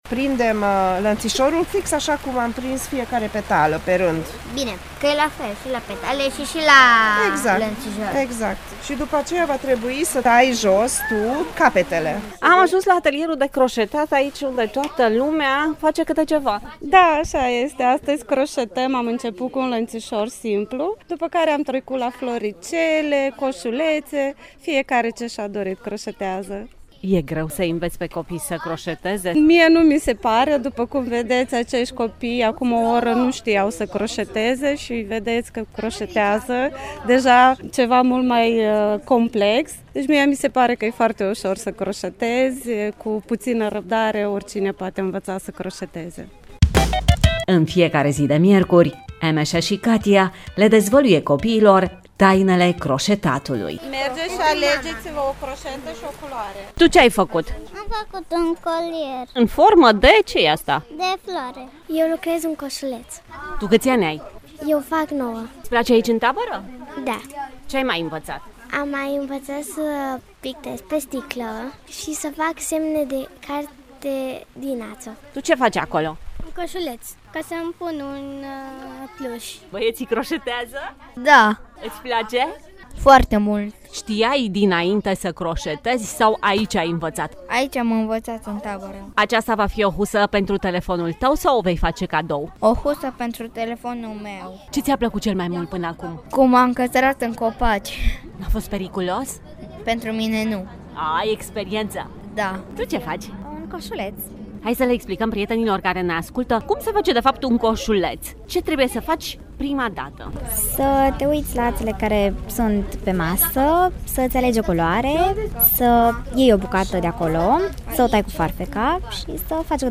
Prima pagină » Reportaje » Vara la Hoia: descoperă meșteșuguri de vacanță în Tabăra Etnografică
O zi de tabără în Parcul Etnografic Romulus Vuia din Cluj Napoca.